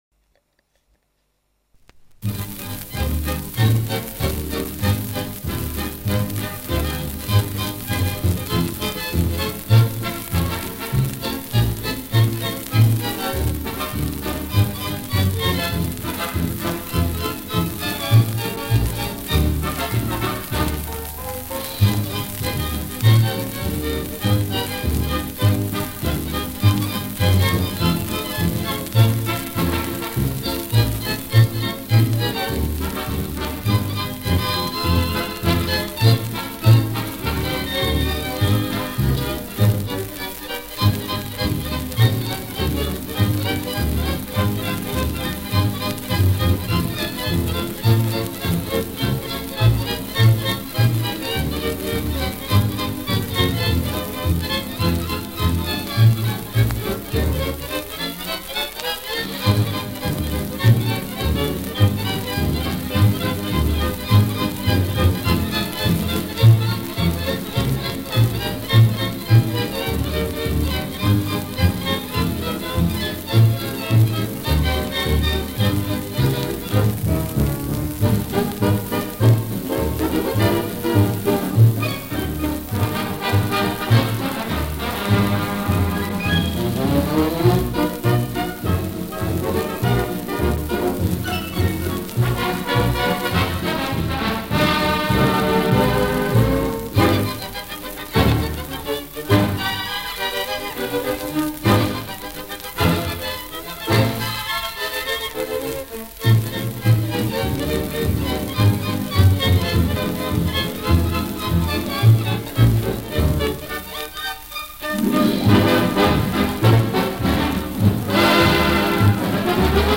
фокстрот